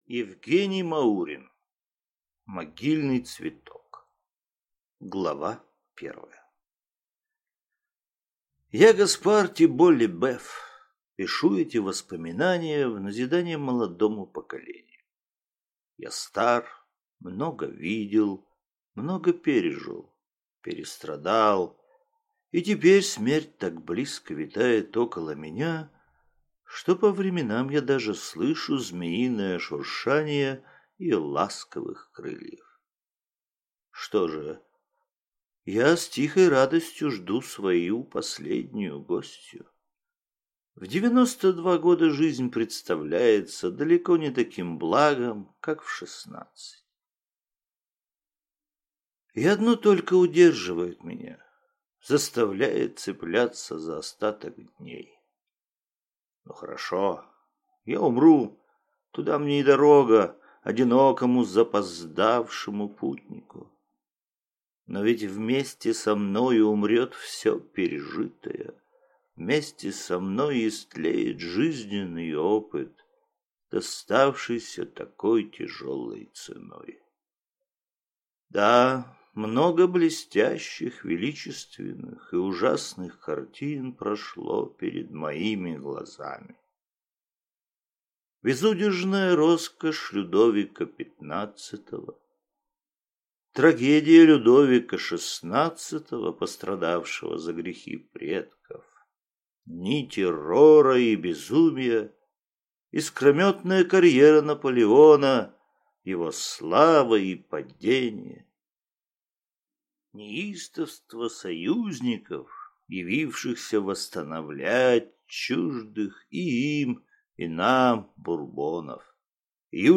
Аудиокнига Могильный цветок | Библиотека аудиокниг